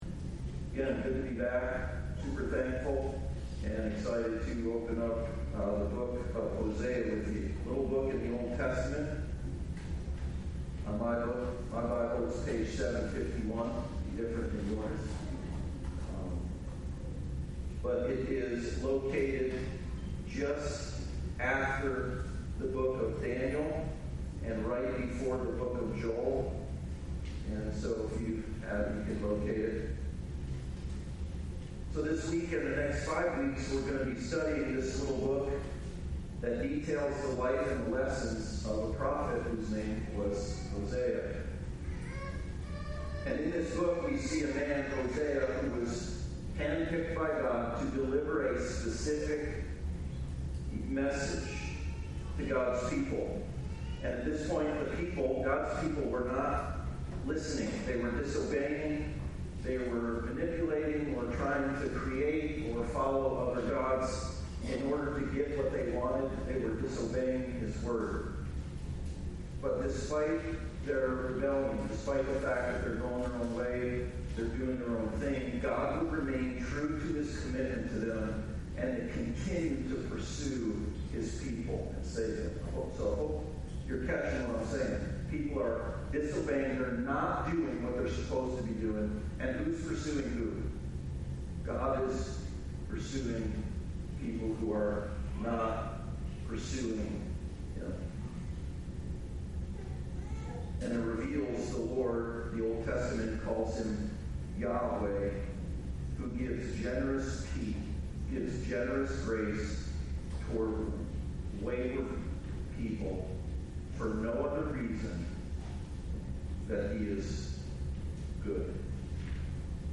Generous Grace Passage: Hosea 1:1-11 Service Type: Sunday Service « A Living Hope Generous Grace